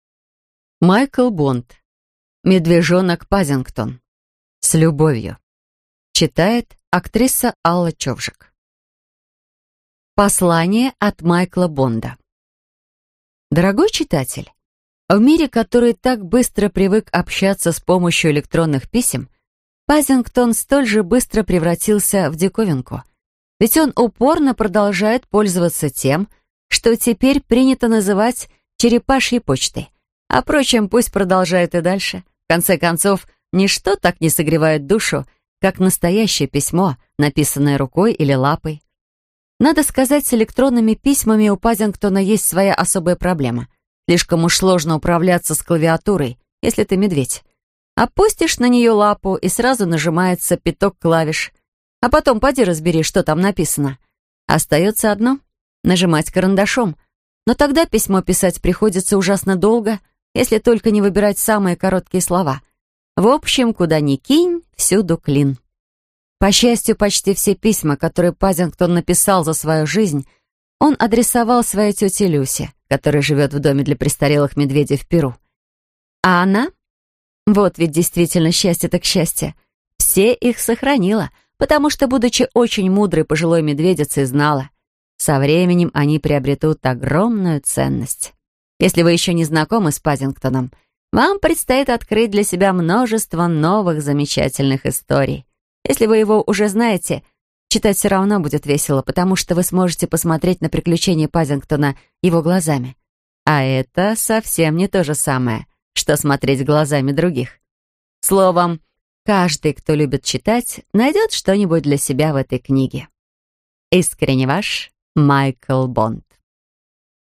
Аудиокнига Медвежонок Паддингтон. С любовью | Библиотека аудиокниг